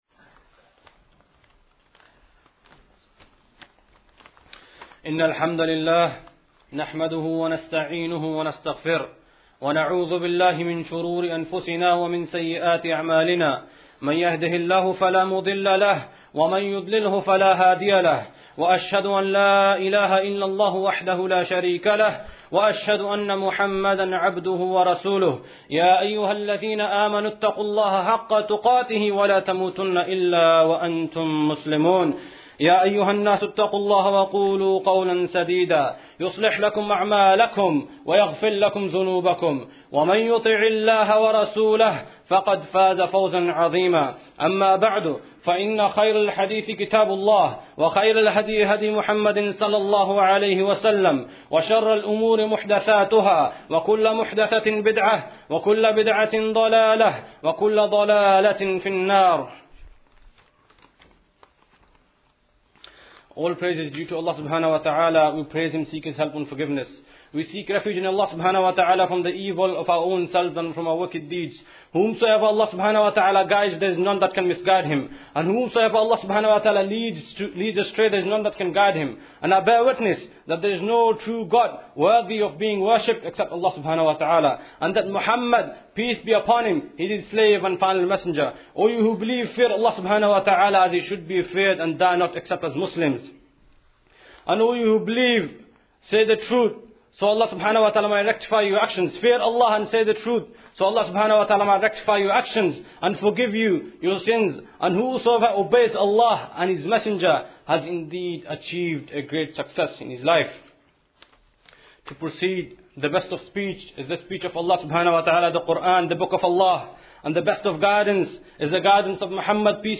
This khutbah describes what happens in the grave. For believers, a happy welcome awaits.